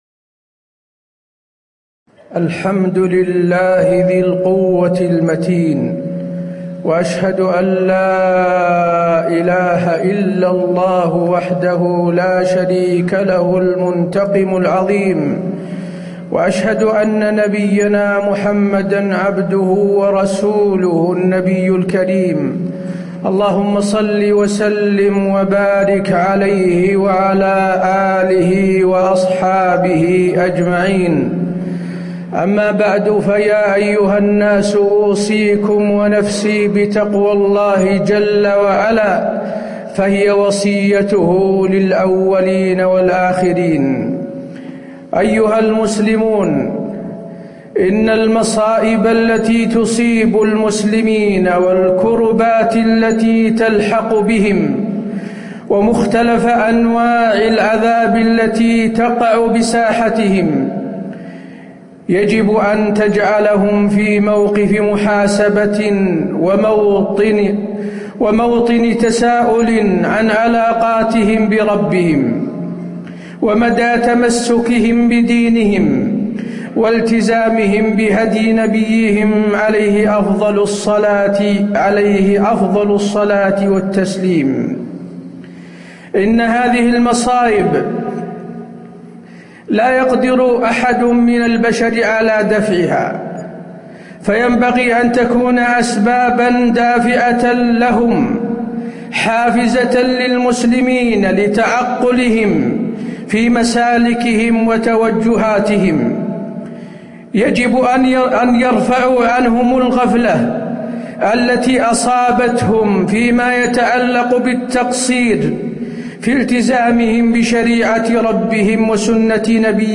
تاريخ النشر ١٧ ربيع الأول ١٤٣٨ هـ المكان: المسجد النبوي الشيخ: فضيلة الشيخ د. حسين بن عبدالعزيز آل الشيخ فضيلة الشيخ د. حسين بن عبدالعزيز آل الشيخ أسباب النصر